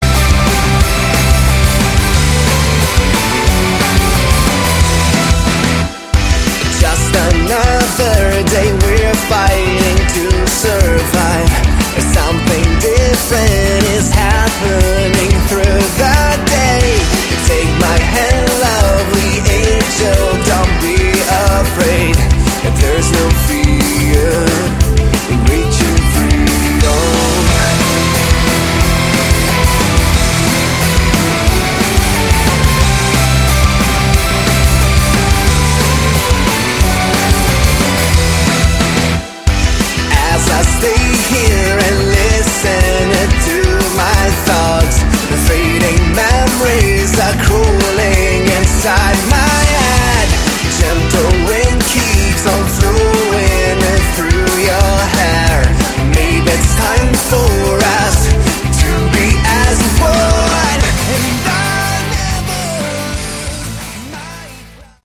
Category: Sleaze Glam
vocals
bass
drums
guitar